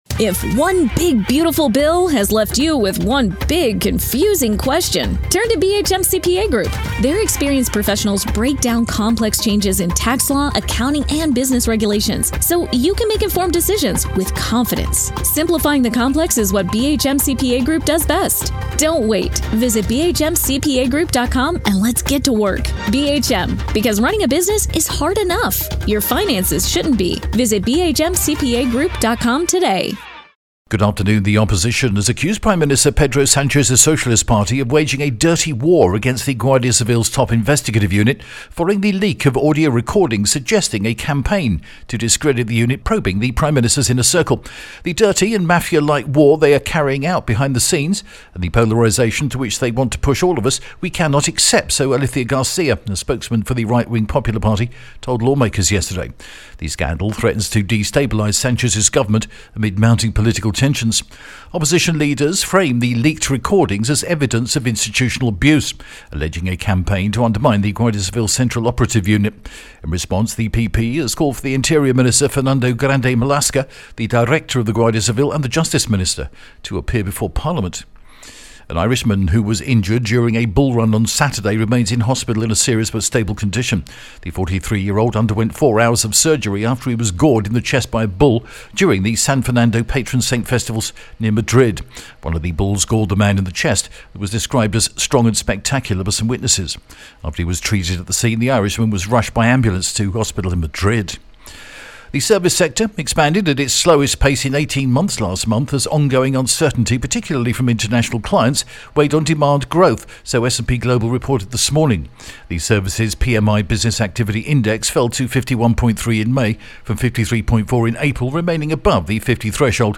The latest Spanish news headlines in English: June 4th 2025